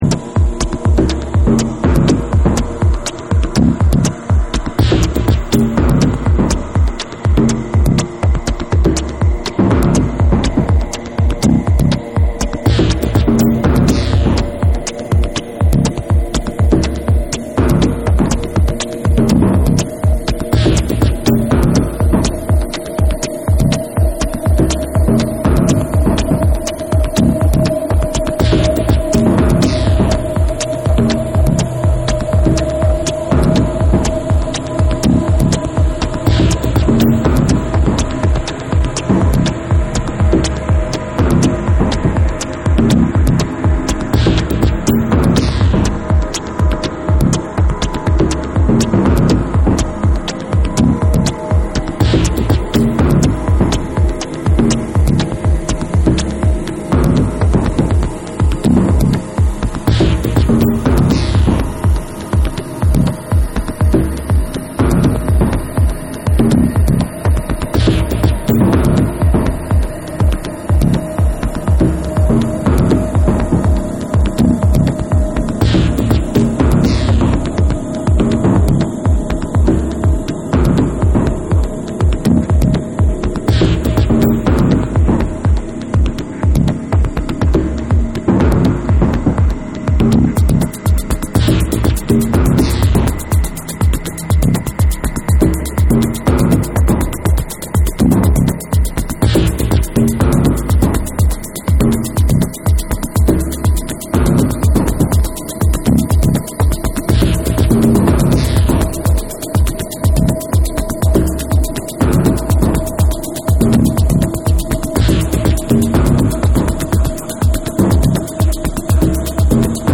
緻密で繊細な音の粒が独創的なミニマル・リズムに絡み展開する音響テクノ・ナンバー
TECHNO & HOUSE